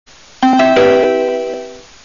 IC4 udkaldslyd (direkte lydlink)
"Standard" udkaldslyd, der bl.a. også benyttes i de nye S-tog. Ifølge DSB er IC4-togsættet endnu ikke blevet udstyret med den "rigtige" DSB-jingle, fordi de er mere fokuseret på driftsmæssige udfordringer på IC4.